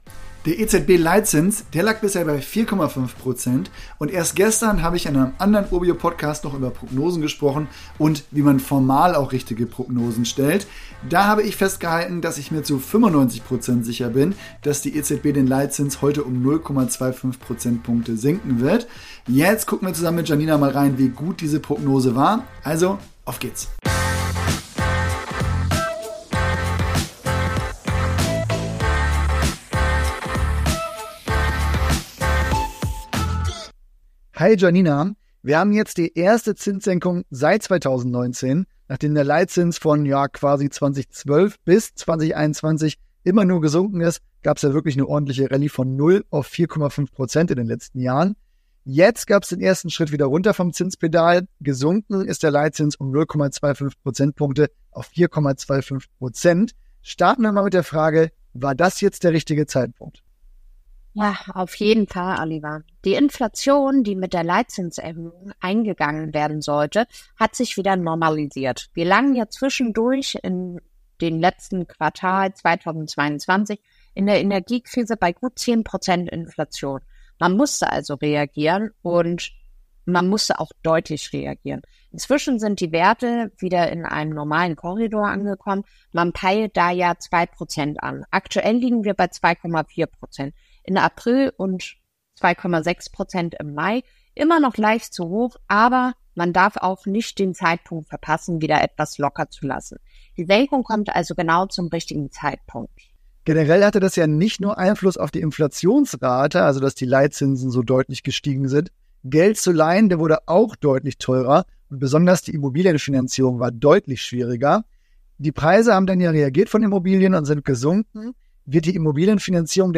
ordnet diese Senkung im Gespräch kurz ein.